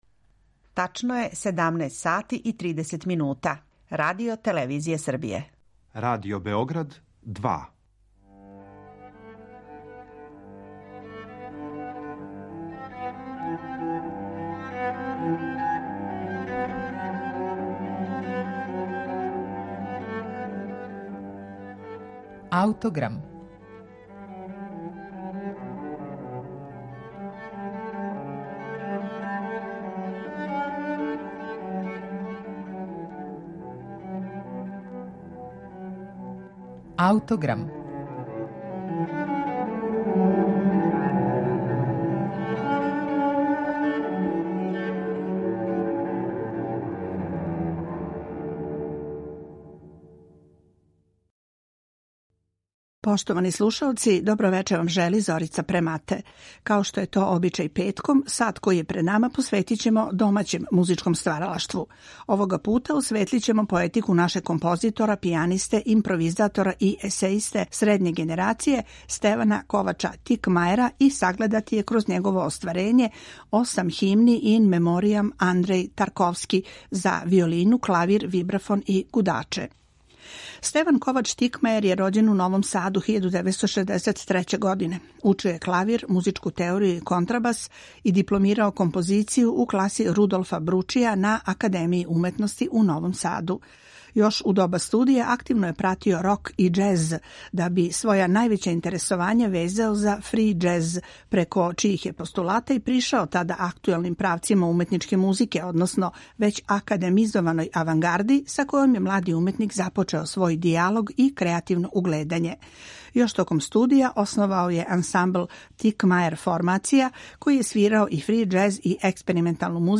за виолину, клавир, вибрафон и гудаче
виолина